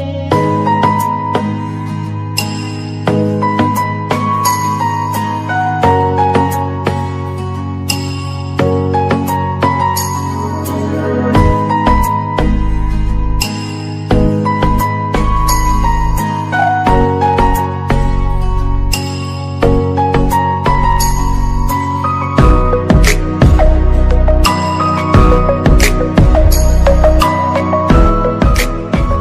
Category: iPhone Ringtones